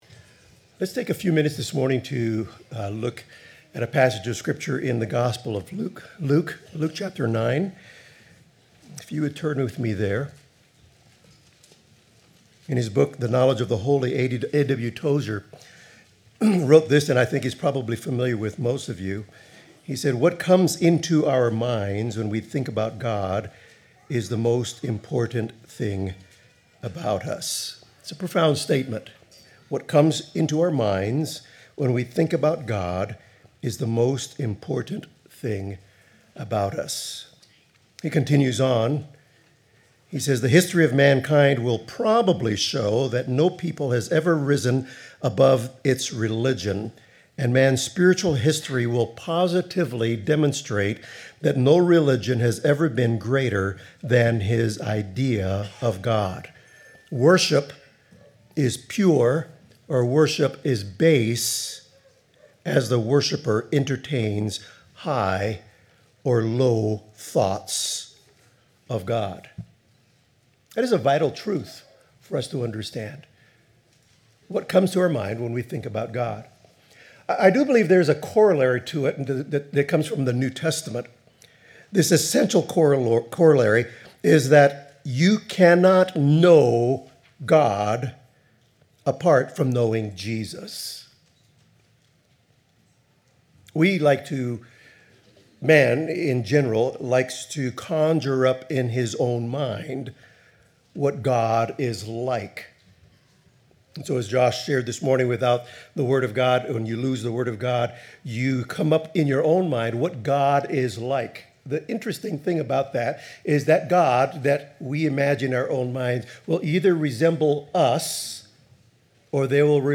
Luke 9:1-23 Service Type: Morning Service What you believe about Jesus affects everything about you